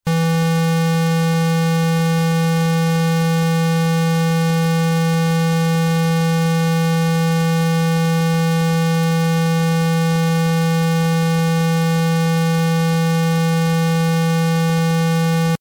Klang 1, Kategorie: Rechteckschwingung (Puls)
Monophone Klänge: